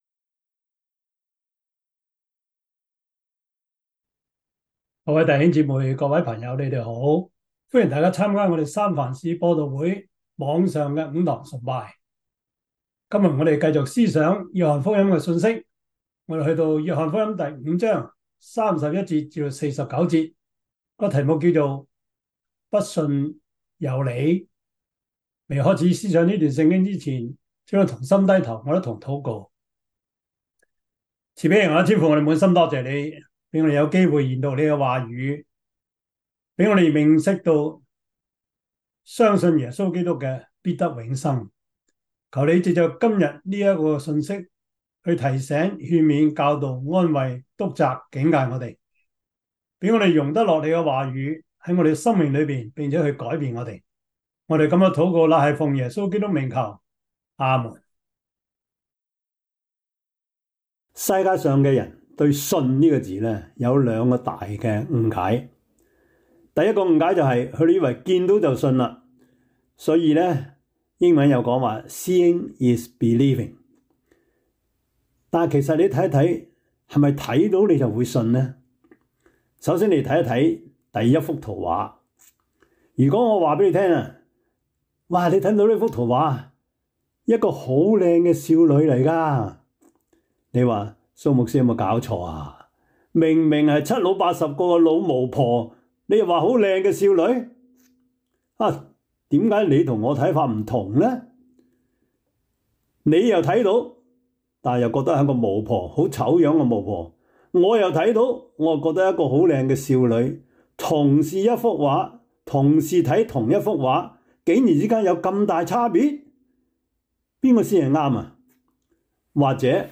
約翰福音 5:31-47 Service Type: 主日崇拜 約翰福音 5:31-47 Chinese Union Version